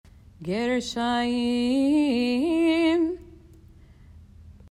Cantillation for High Holidays
6._gershayim.mp3